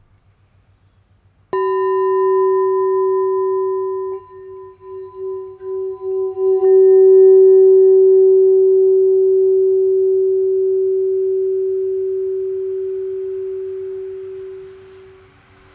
F Note 5″Singing Bowl